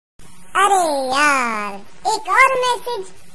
message ringtone comedy